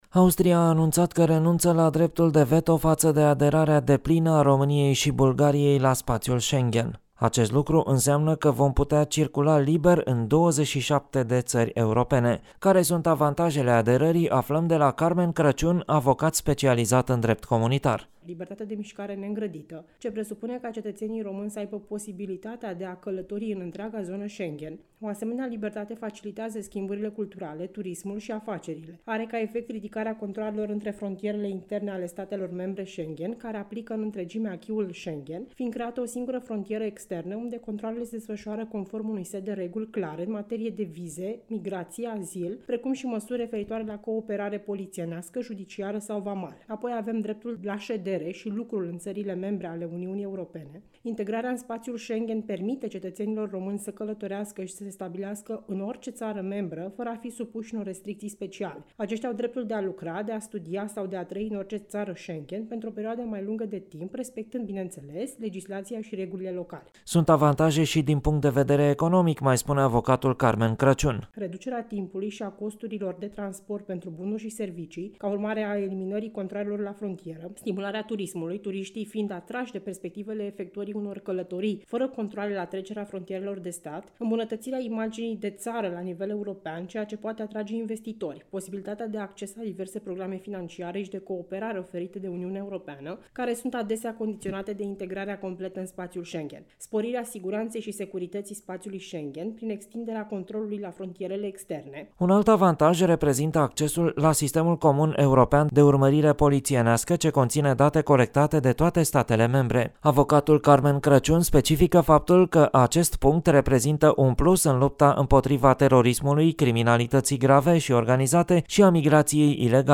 O parte dintre avantajele aderării depline la spațiul comunitar ni le spune avocatul